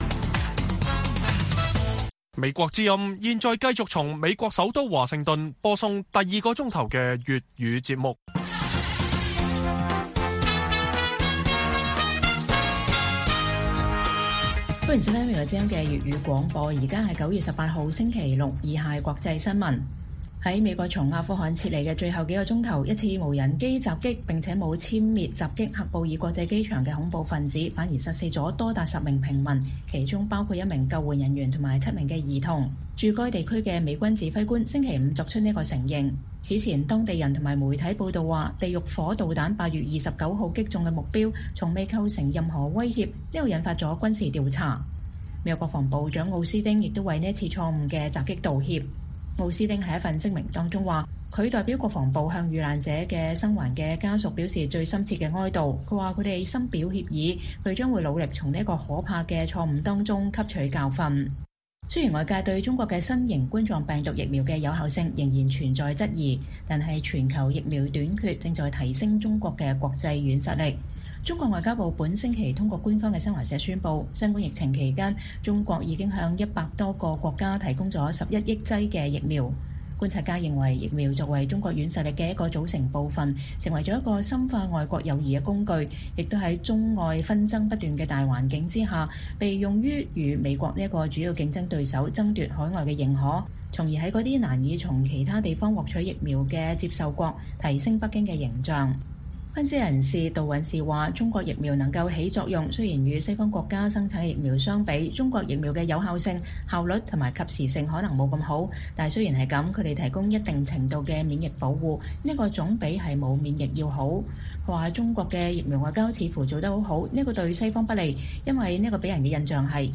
粵語新聞 晚上10-11點:美軍承認喀布爾無人機襲擊是“悲劇性的錯誤”